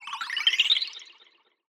SFX_Magic_Healing_03.wav